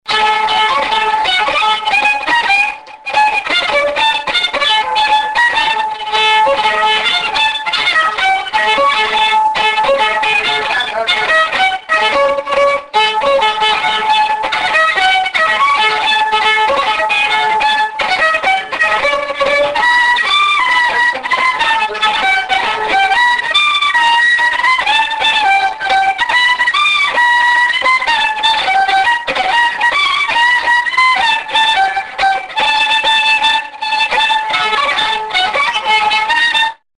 (region przeworski)
skrzypcach